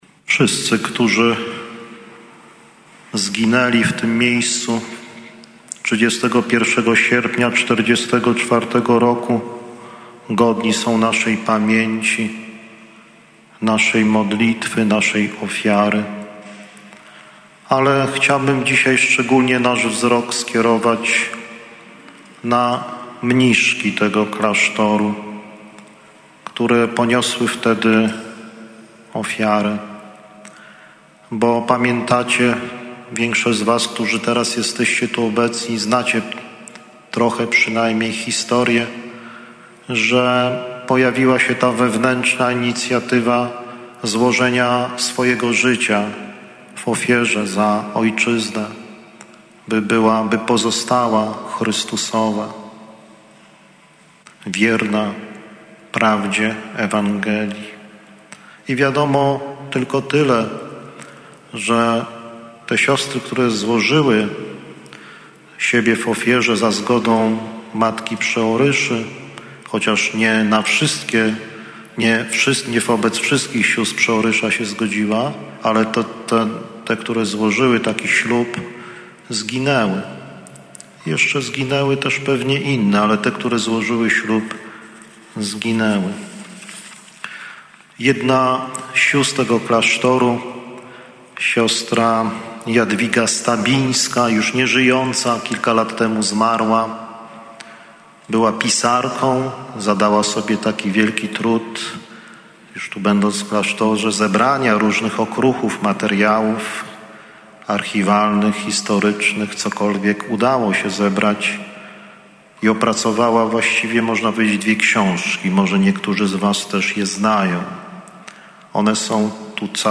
Msza św. za poległych w Powstaniu Warszawskim
Homilia